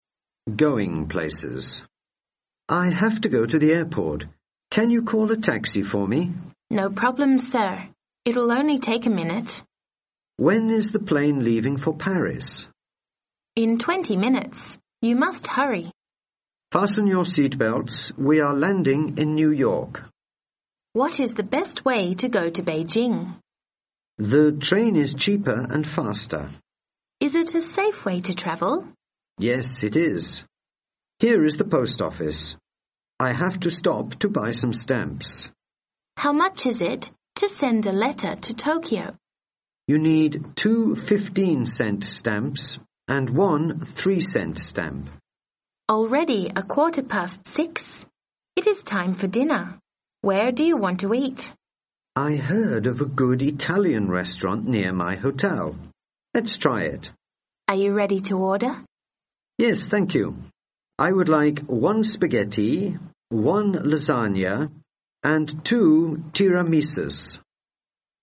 Dialogue：Going Places